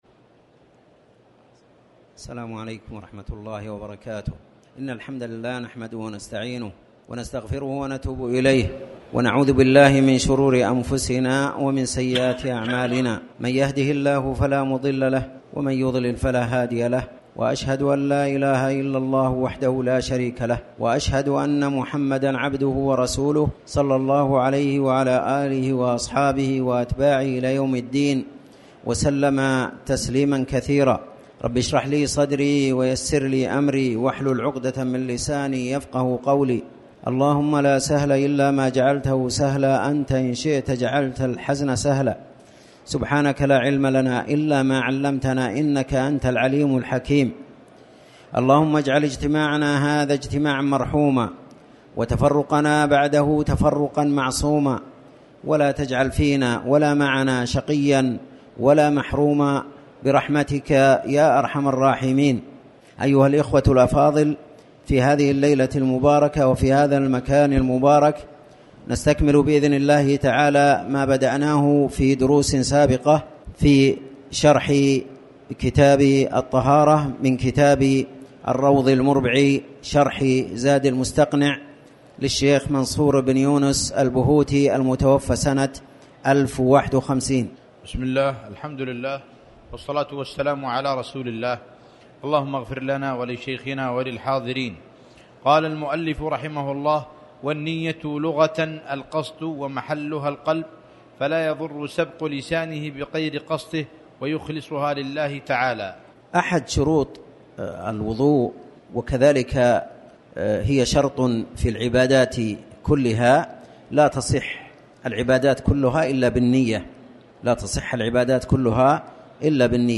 تاريخ النشر ١٦ رجب ١٤٣٩ هـ المكان: المسجد الحرام الشيخ